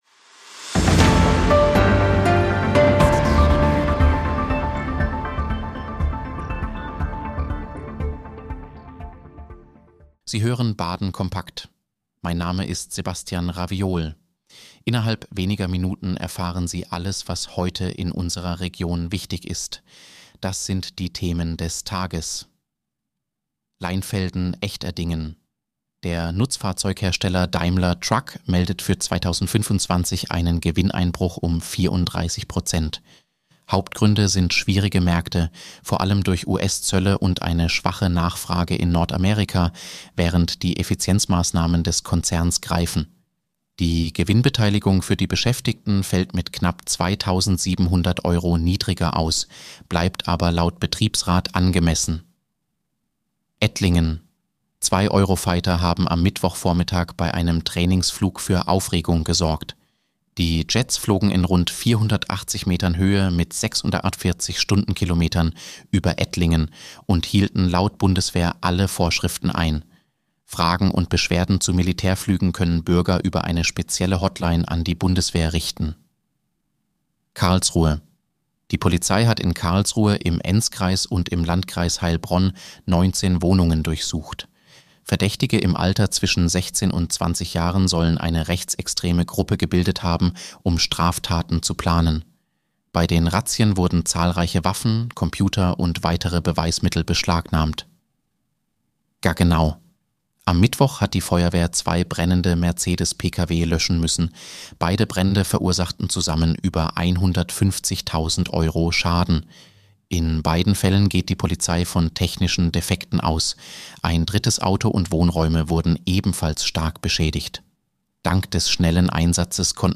Nachrichtenüberblick: Gewinneinbruch bei Daimler Truck
News für Baden